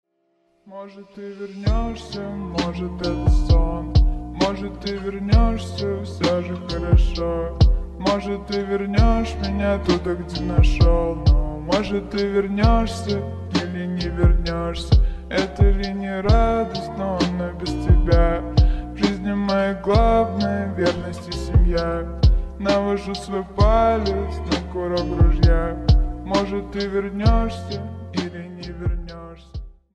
Рингтоны Ремиксы » # Рэп Хип-Хоп Рингтоны